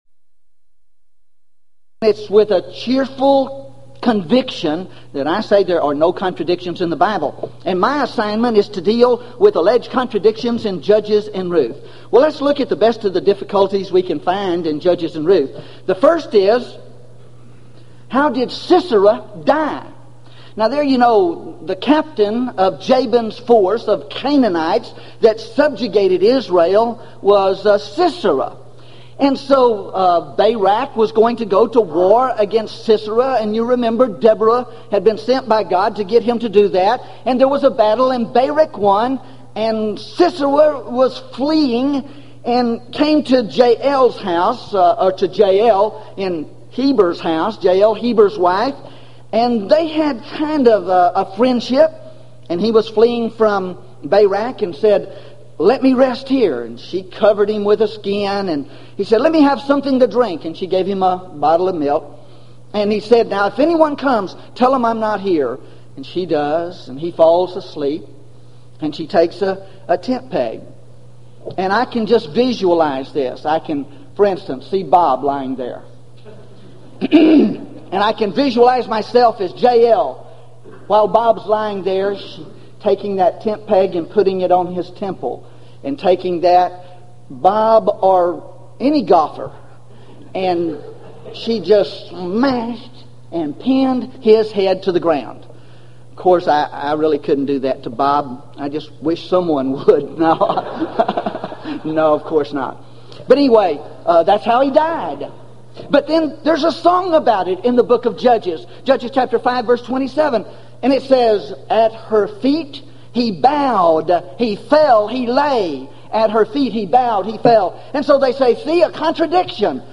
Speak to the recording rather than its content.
Event: 1995 Gulf Coast Lectures